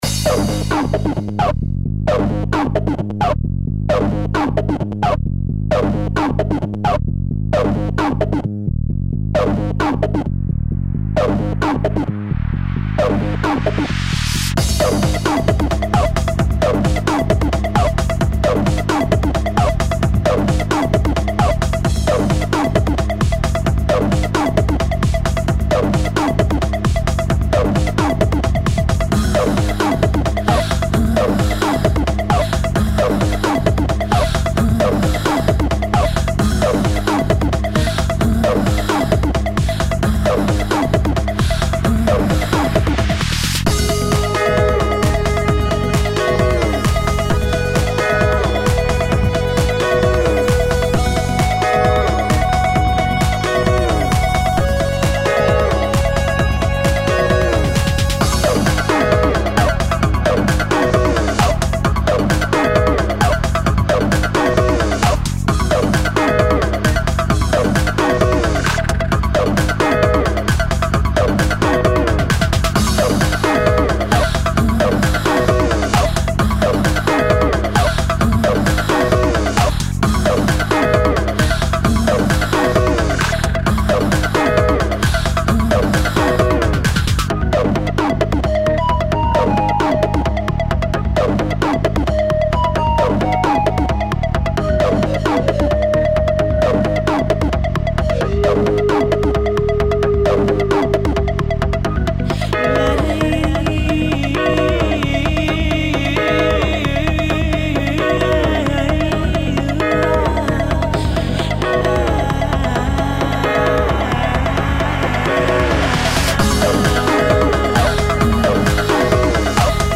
dance/electronic
Techno
Trance